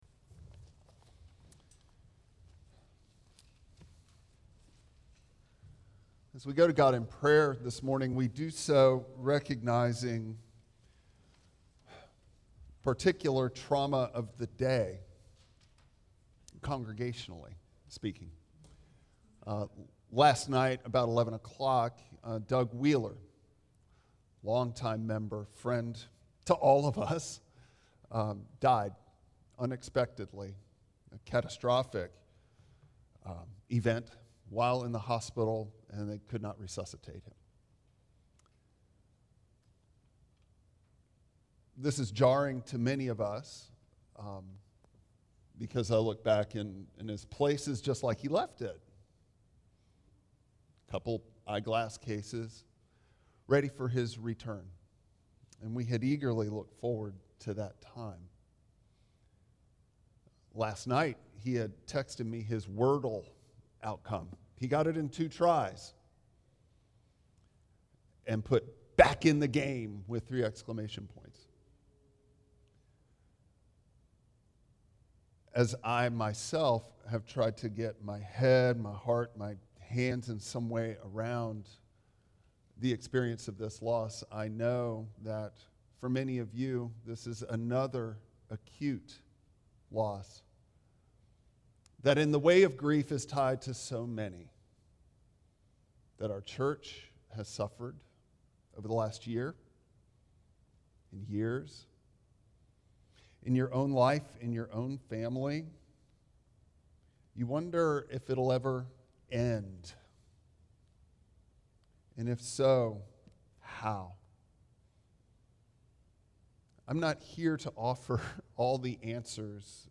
Passage: Luke 4:16-21 Service Type: Traditional Service